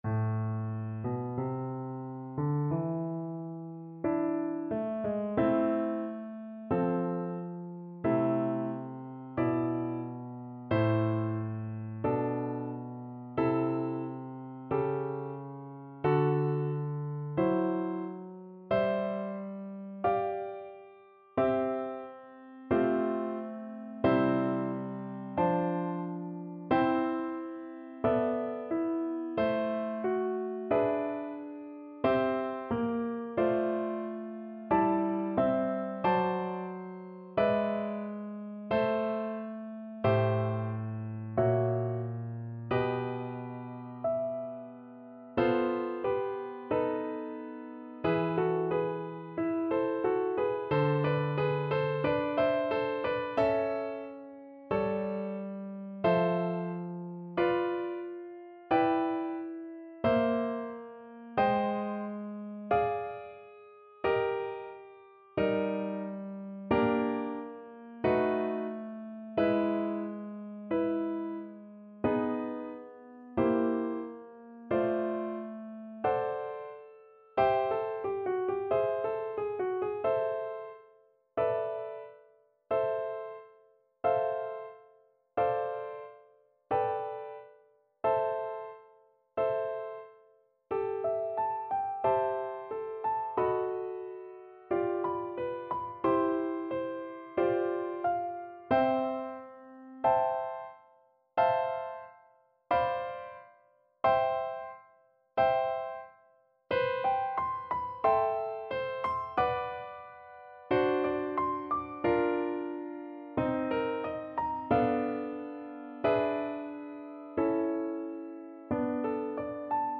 Adagio, molto tranquillo (=60) =45
4/4 (View more 4/4 Music)
Classical (View more Classical Viola Music)